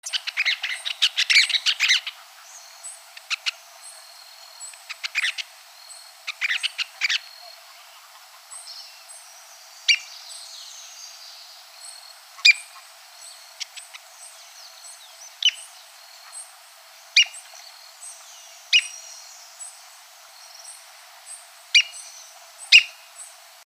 Fluvicola_nengeta-58811.mp3